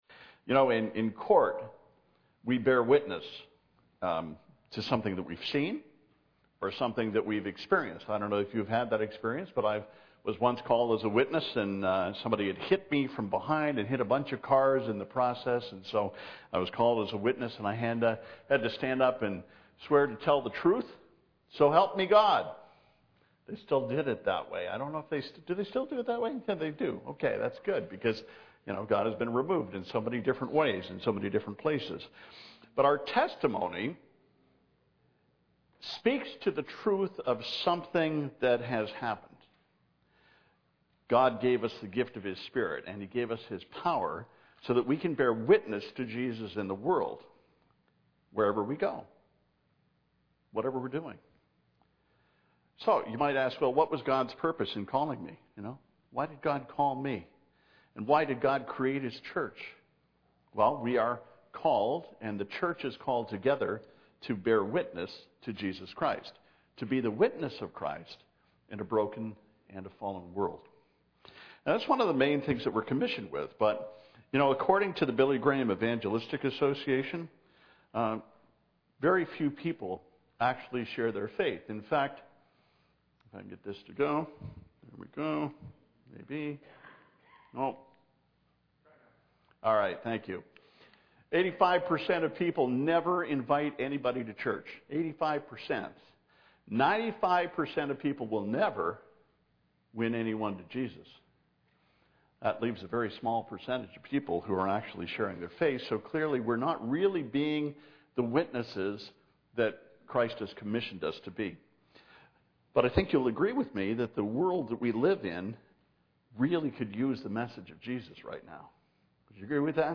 Series: I HAVE A PURPOSE Part 2 “Be His Witness” Acts 1:8 « FABIC Sermons